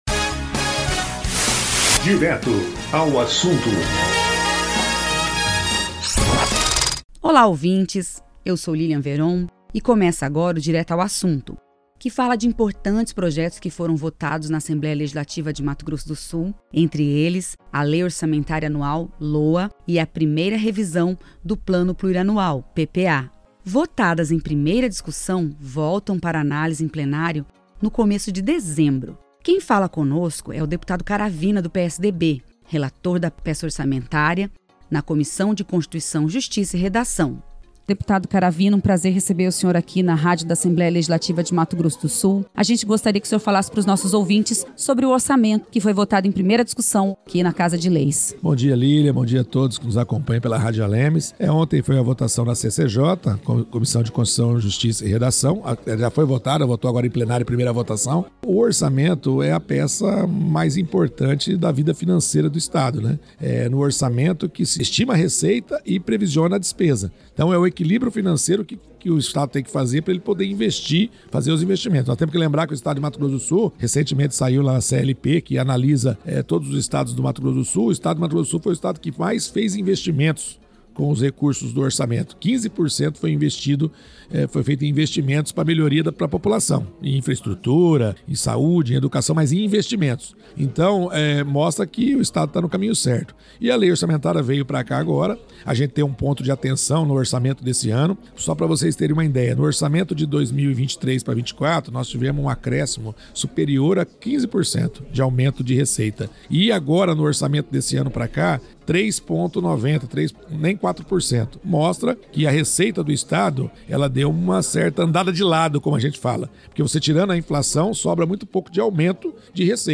Relator da peça orçamentária e do PPA, deputado Caravina do PSDB explica a importância do projeto e das emendas parlamentares, além de projeto para melhorias de estradas em Mato Grosso do Sul.